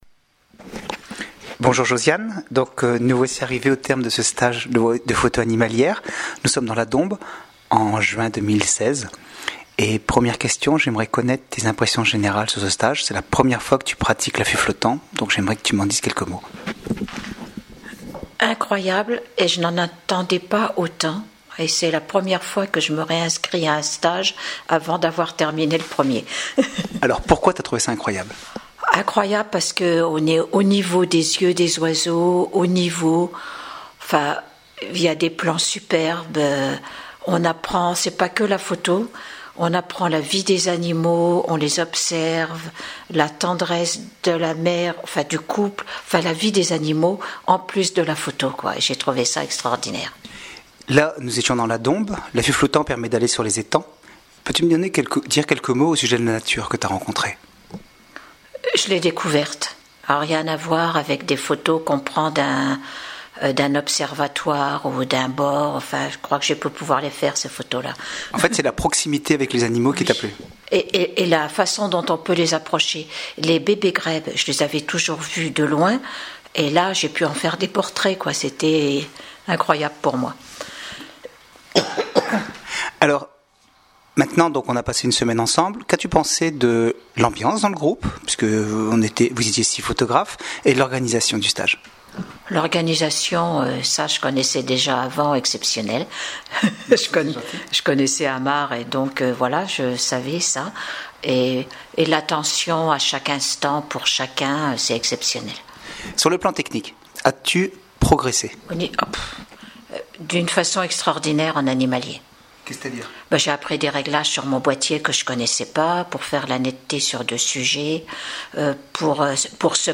Le commentaire écrit et oral des participants